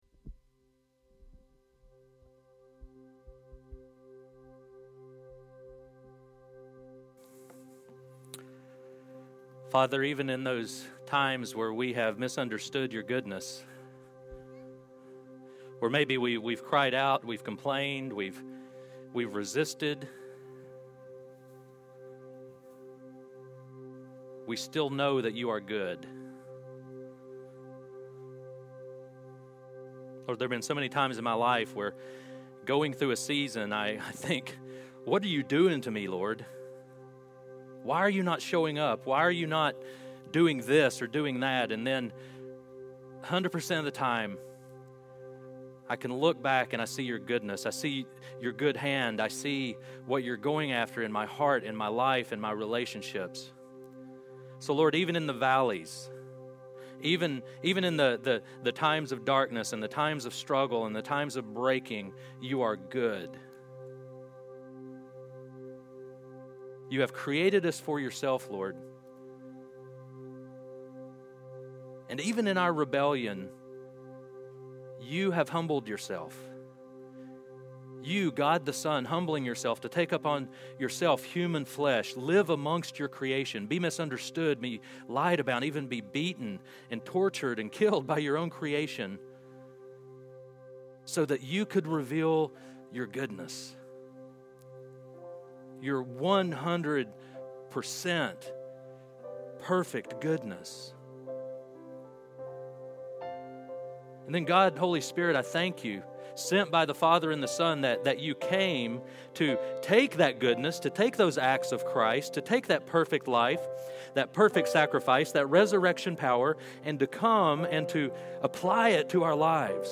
Sermons | Restoration Community Church